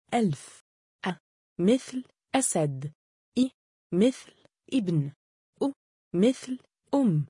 • With a Fatha and Hamza (أَ): This is the most common sound. It’s a short “a” sound, like the “a” in “apple” or “at.”
• Short sound (أ): أب (Ab – Father)
• Long sound (آ): آدم (Aadam – Adam)